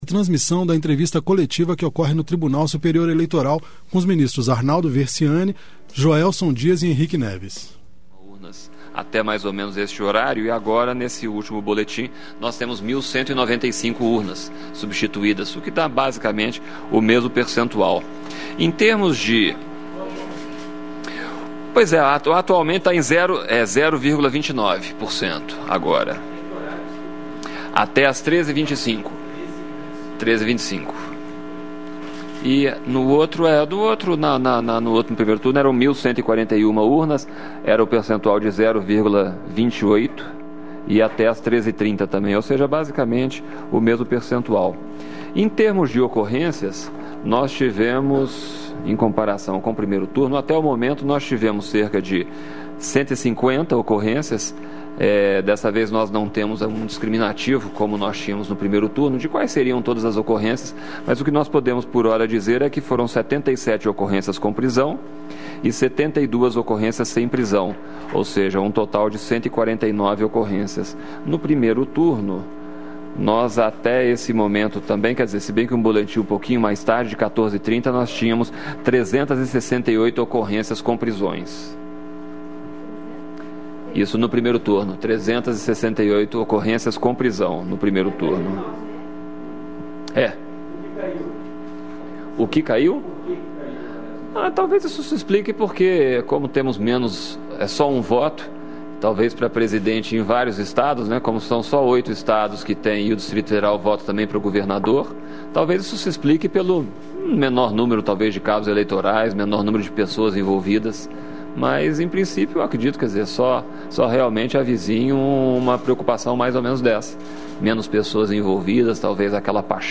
Entrevista com os ministros Arnaldo Versiani, Joelson Dias e Henrique Neves.